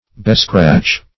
Bescratch \Be*scratch"\